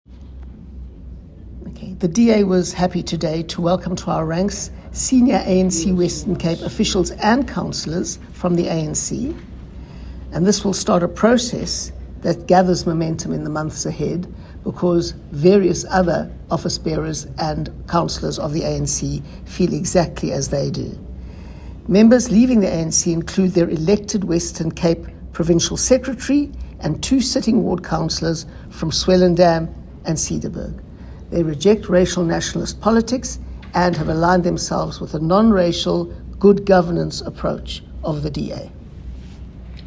Soundbite by Helen Zille.